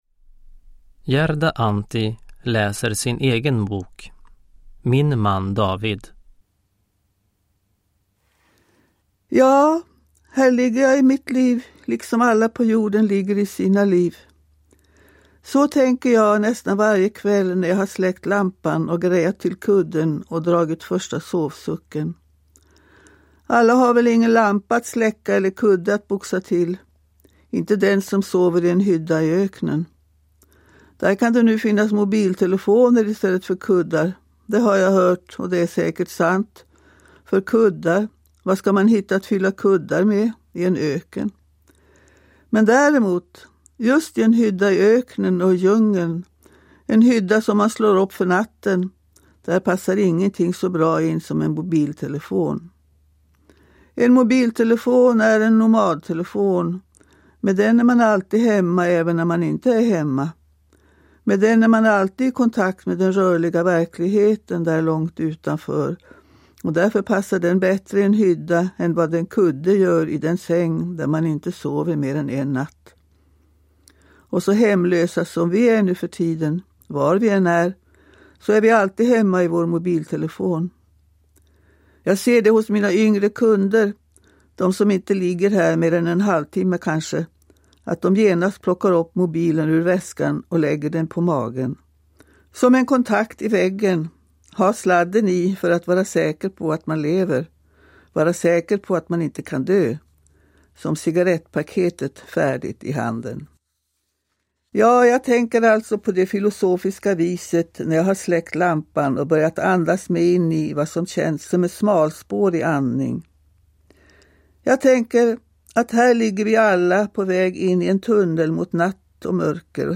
Min man David – Ljudbok – Laddas ner
Uppläsare: Gerda Antti